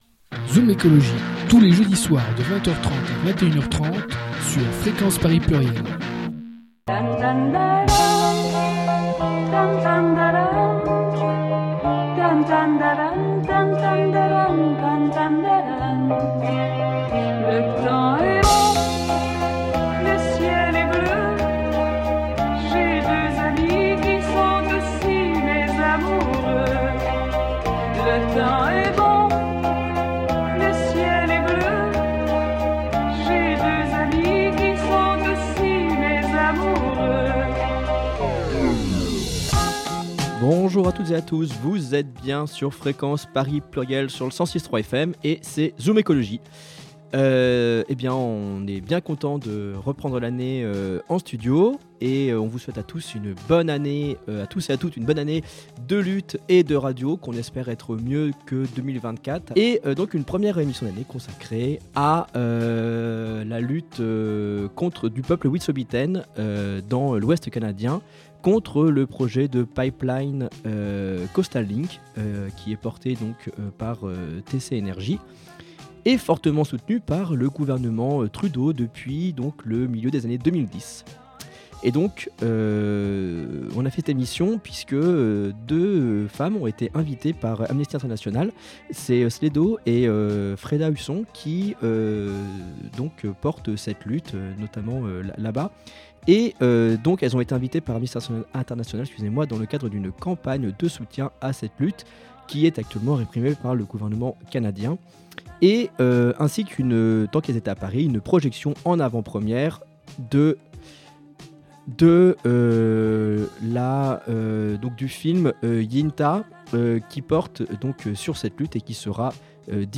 1 Yintah - Rencontre avec des militantes Wet'suwet'en en lutte contre le Coastal Link Pipeline au Canada Play Pause 7h ago Play Pause Lejátszás később Lejátszás később Listák Tetszik Kedvelt — Une émission hivernale consacrée à la lutte menée par les communautés Wet'suwet'en contre le Coastal Link Pipeline dans le grand Est canadien à l'occasion de la sortie du documentaire Yintah et de la venue en France de deux représentantes de ces communautés. Émissions en deux temps avec une première interview